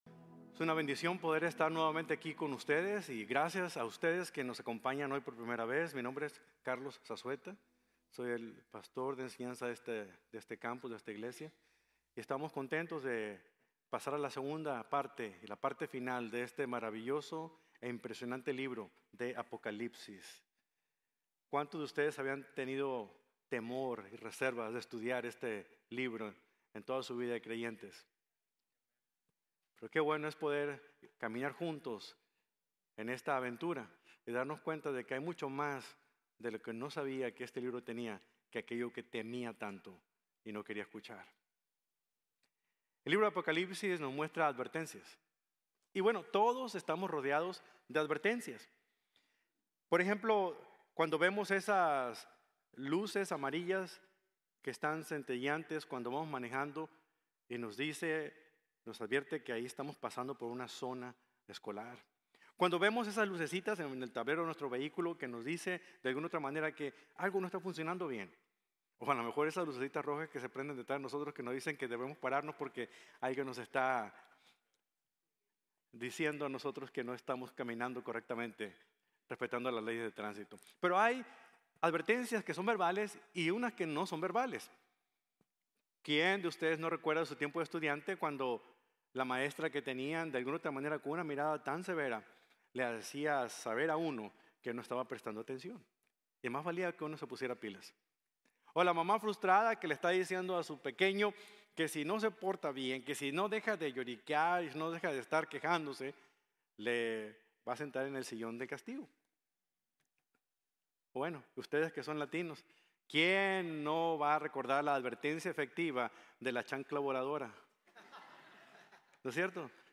Dios de Ira y Juicio | Sermon | Grace Bible Church